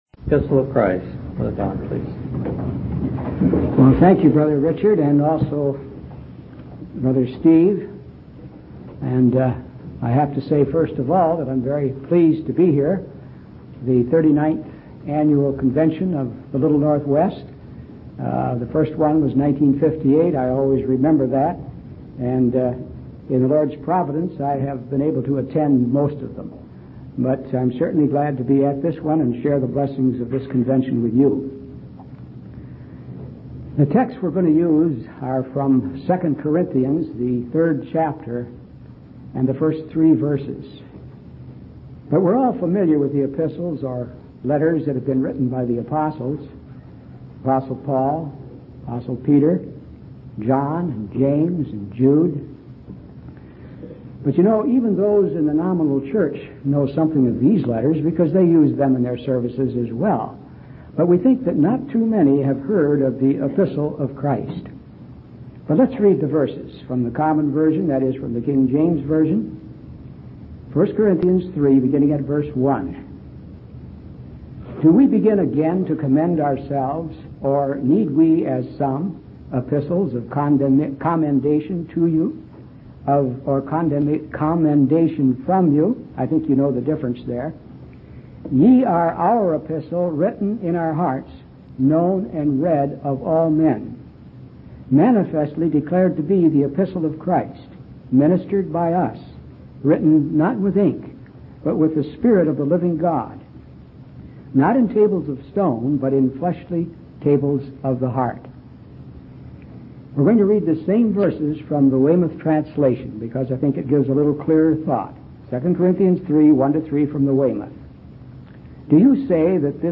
From Type: "Discourse"
Bellingham Convention 1996